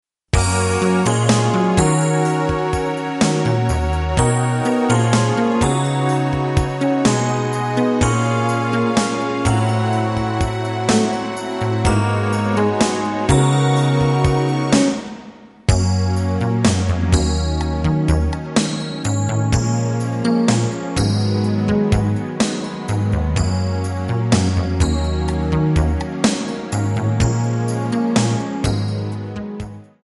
Backing track files: Pop (6706)